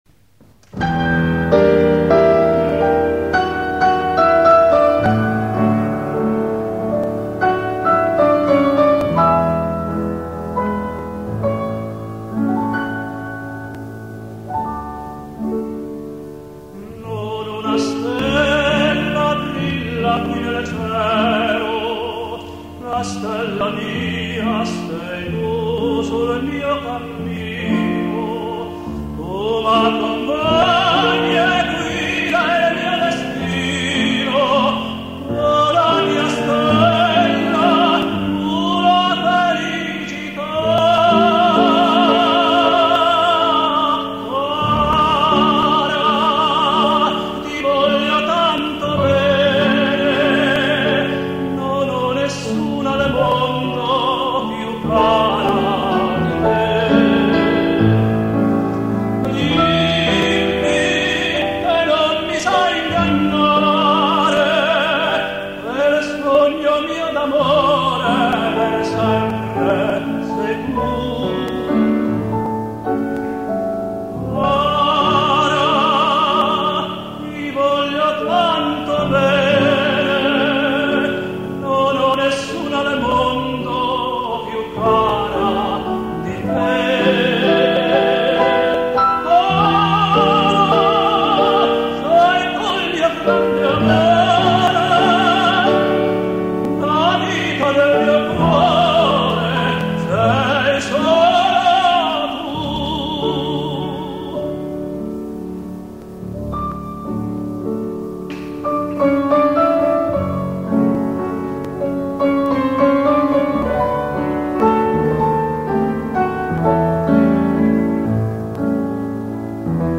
CONCERTO CON PIANOFORTE
TOKYO - BEETHOVEN HALL